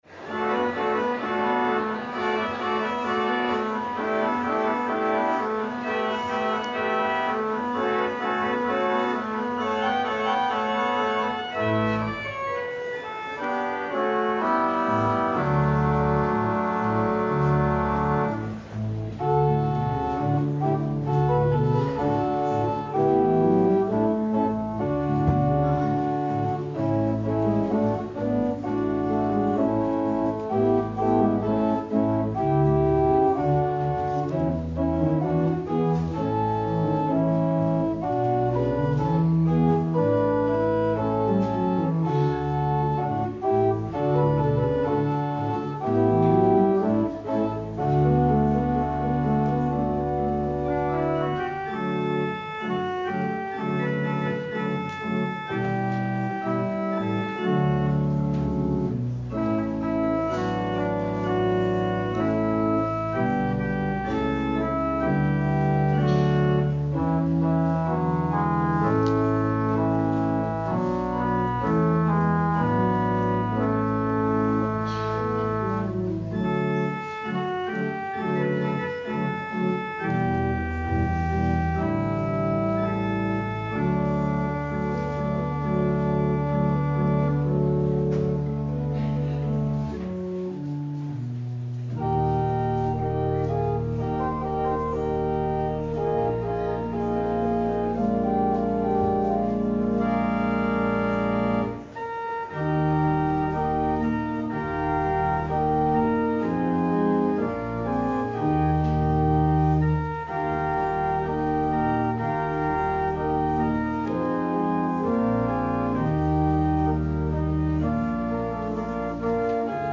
Christmas Eve: Children’s Service (5pm)